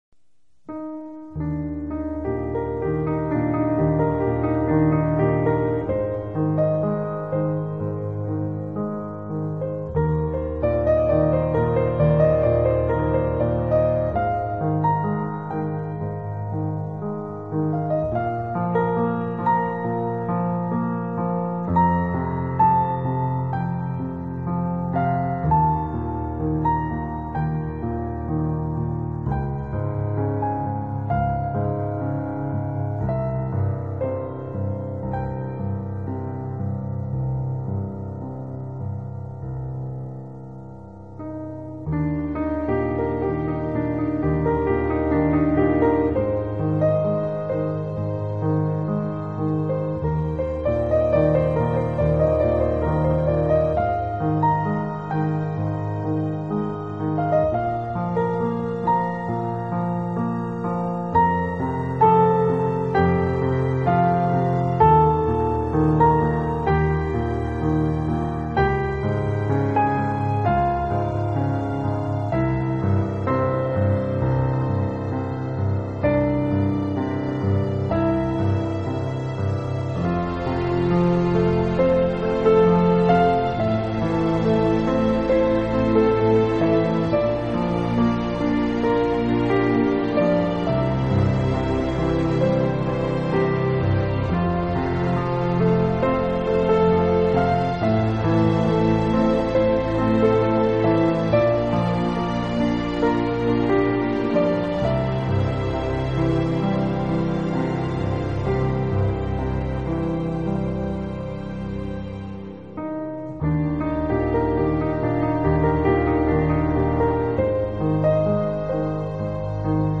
他的唯美钢琴、轻柔的钢琴，非常有感觉，洗涤心灵。